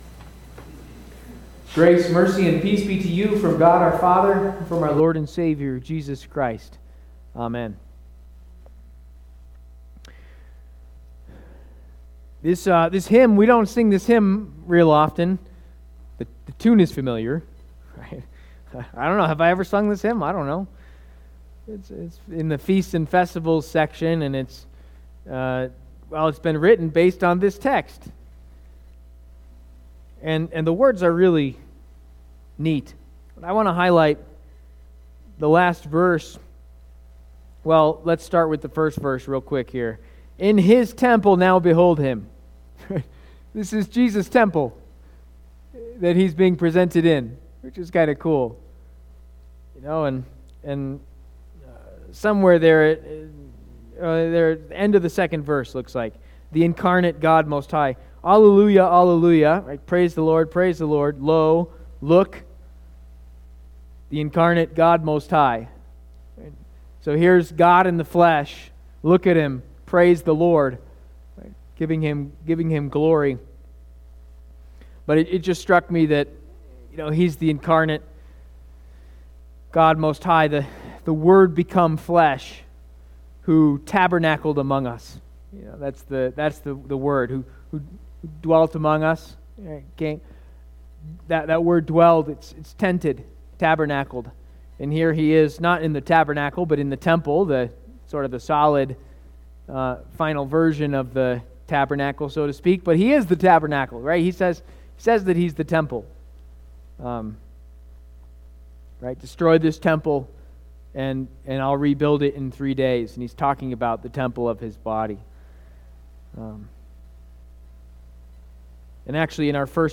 Please note that due to a technical issue while recording, this message ends abruptly a bit prior to it's actual conclusion.